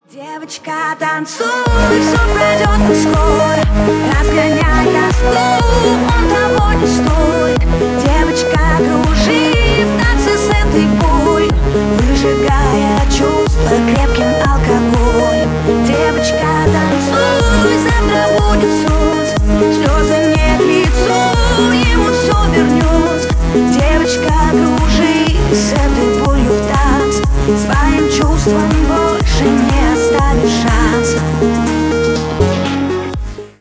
Ремикс # Поп Музыка